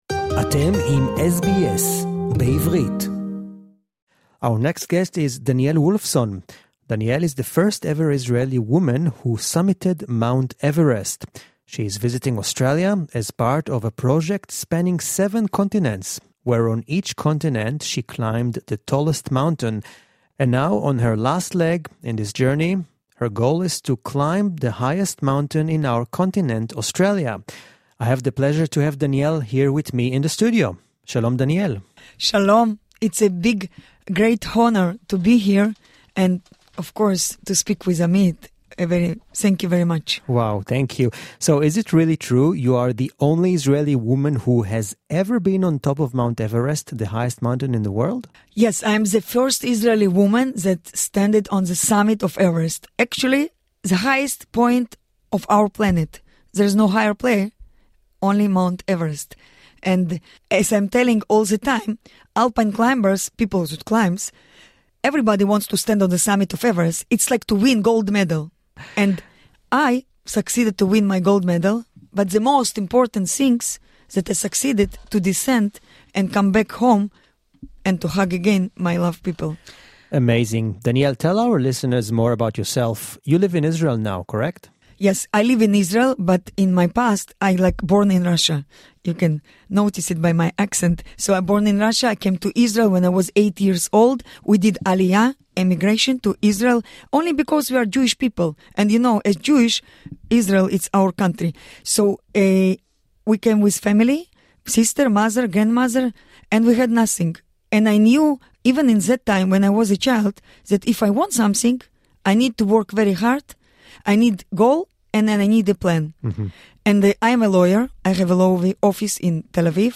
(English interview)